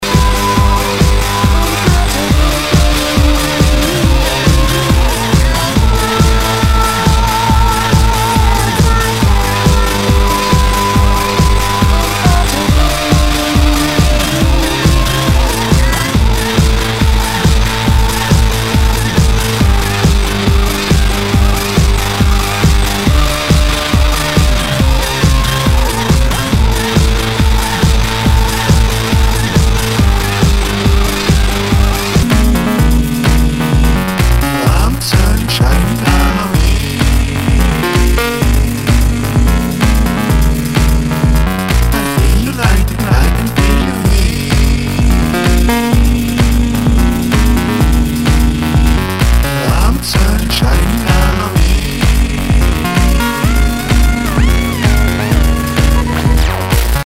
HOUSE/TECHNO/ELECTRO
ナイス！エレクトロ?テクノ！